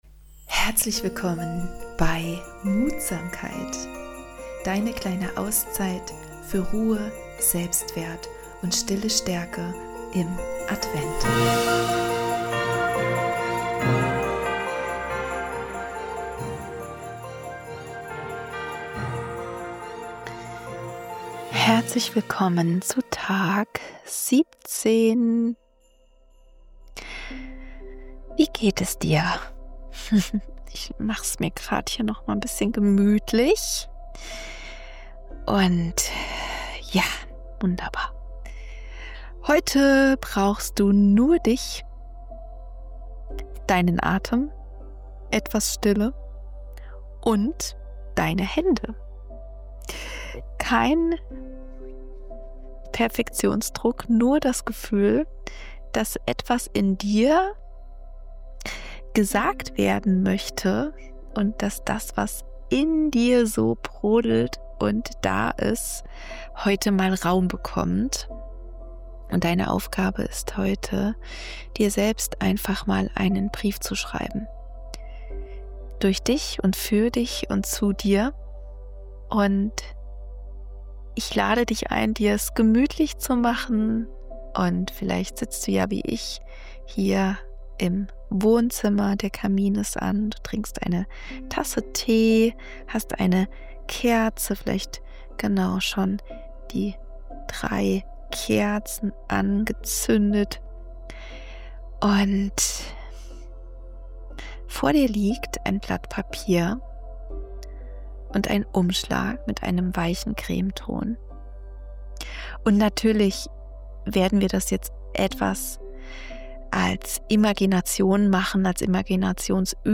Eine sanfte Imagination hilft dir, Kontakt mit deiner inneren Stimme aufzunehmen ehrlich, weich und ohne Druck.